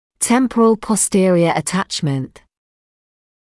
[‘tempərəl pɔs’tɪərɪə ə’tæʧmənt][‘тэмпэрэл пос’тиэриэ э’тэчмэнт]височное заднее прикрепление